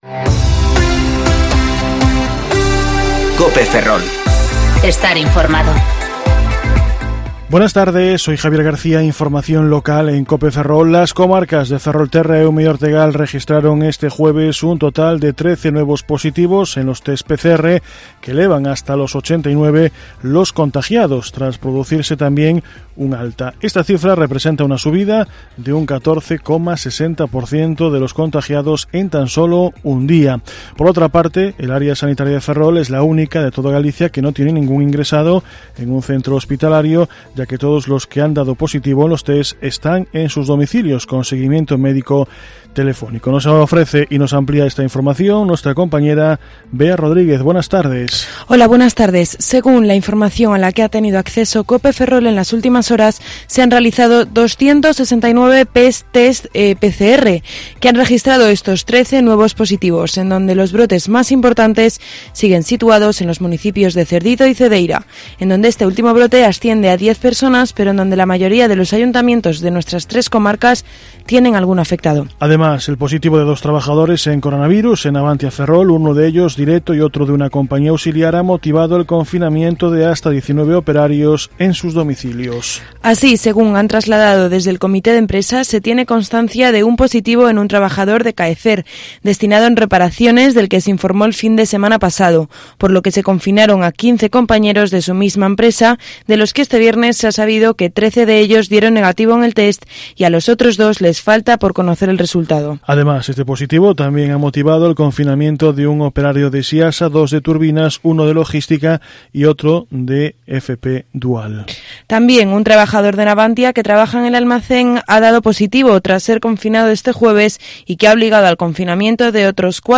Informativo Medía COPE Ferrol - 21/8/2020 (De 14,20 a 14,30 horas)